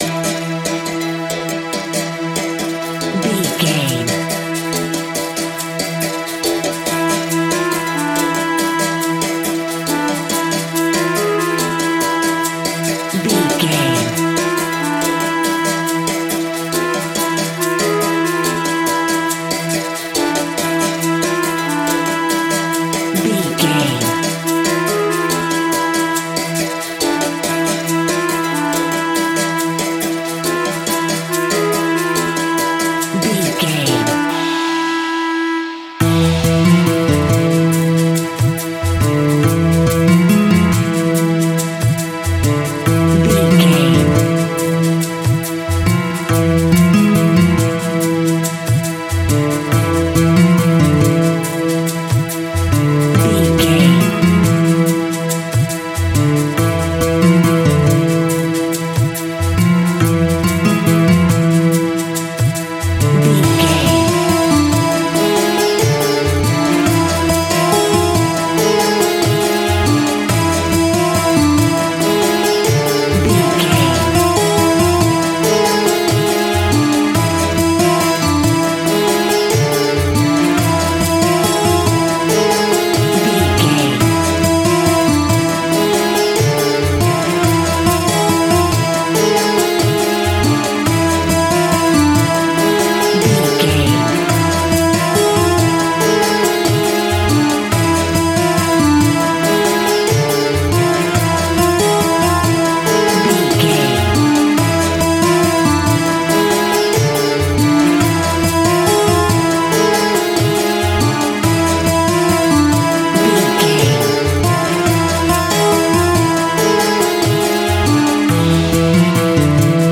Aeolian/Minor
Fast
Exotic
sitar
bongos
sarod
tambura